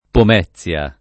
Pomezia [ pom $ZZL a ] top. (Lazio)